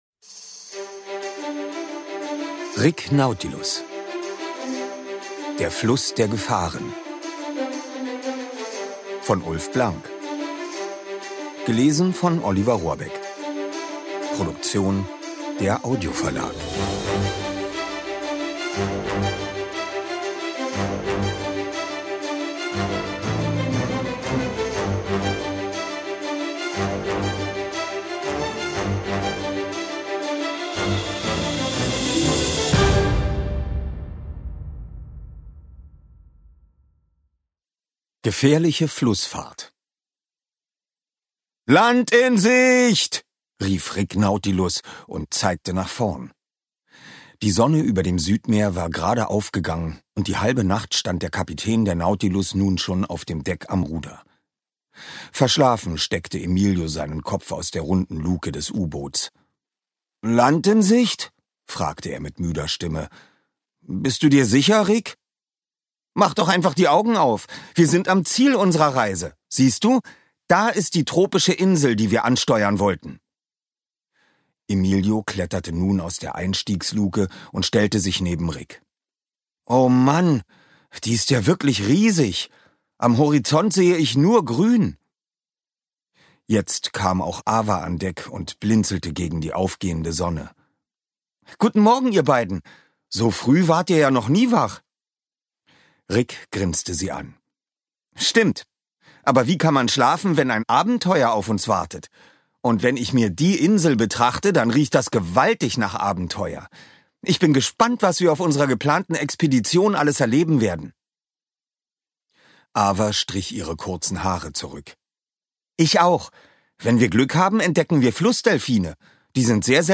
Ungekürzte Lesung mit Musik mit Oliver Rohrbeck (2 CDs)
Oliver Rohrbeck (Sprecher)